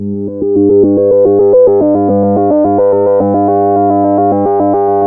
JUP 8 G3 11.wav